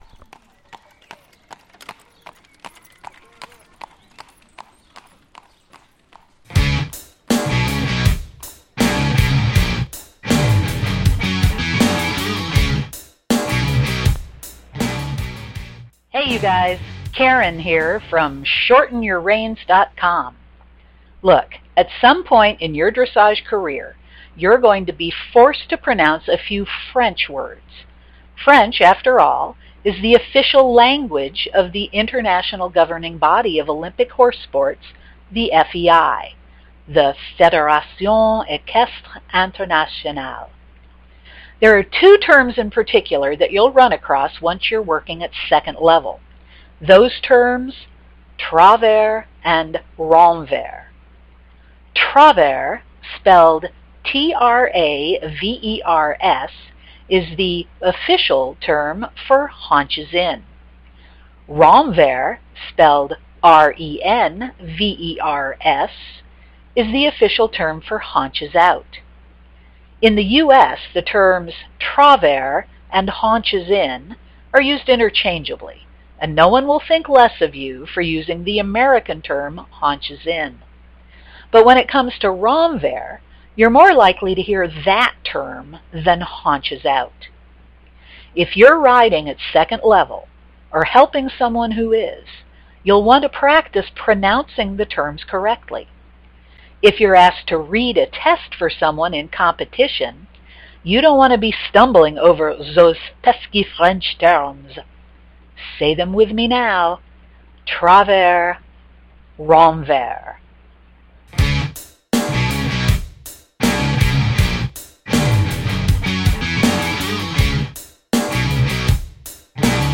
Dressage Pronunciation 101